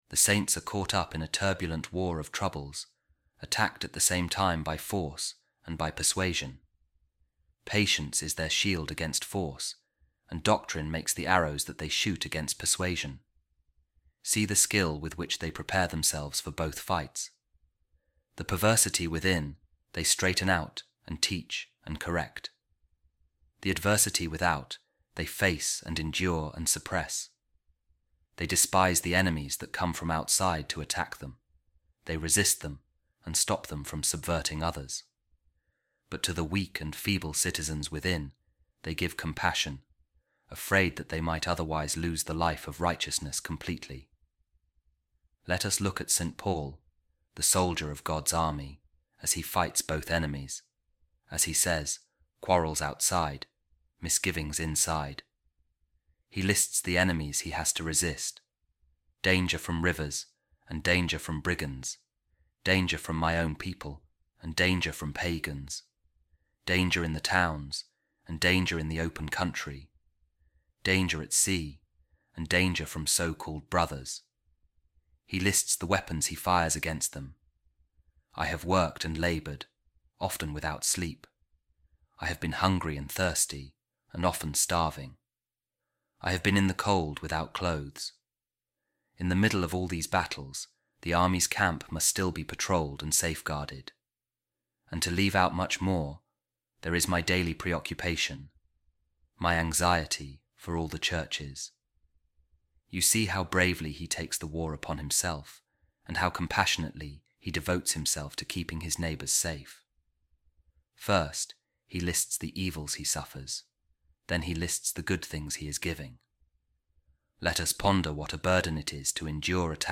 A Reading From The Commentary Of Pope Saint Gregory The Great On The Book Of Job | Fights Without And Fear Within